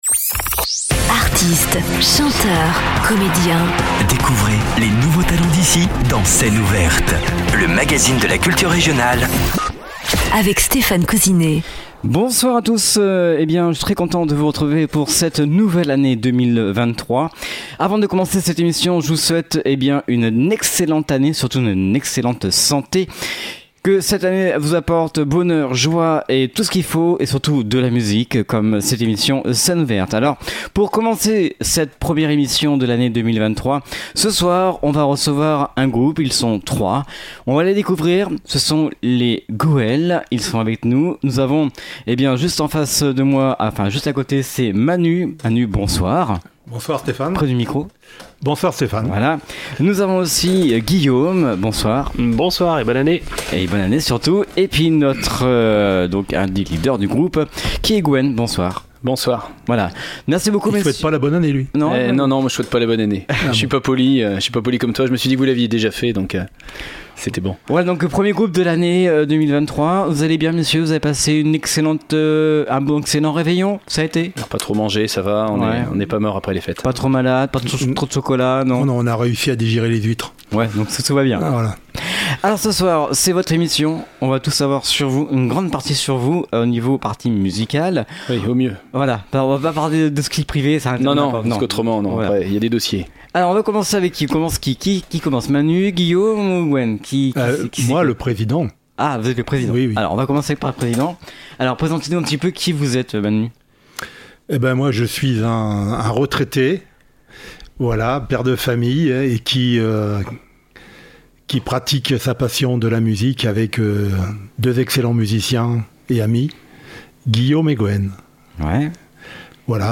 groupe musical trio, pop/rock « marin » Rochelais